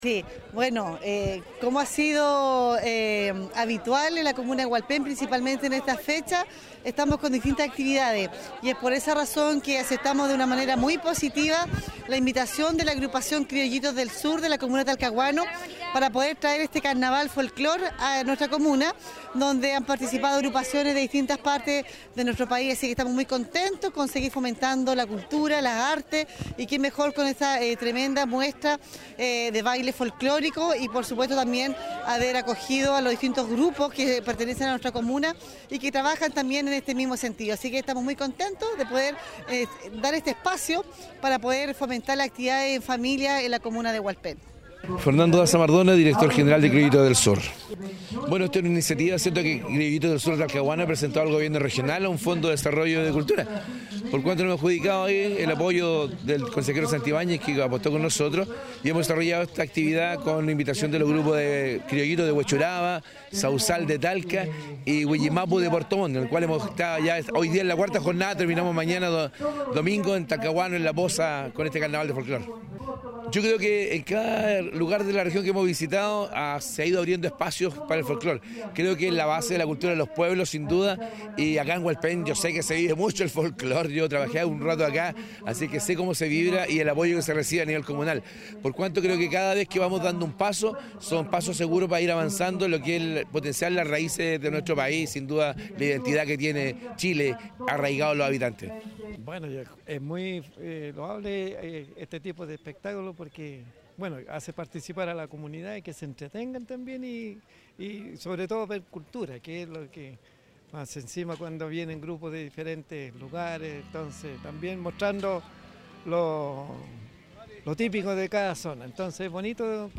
CUÑAS MUESTRA CARNAVAL DEL FOLCLORE HUALPEN 2020
CUÑAS-MUESTRA-CARNAVAL-DEL-FOLCLORE-HUALPEN-2020.mp3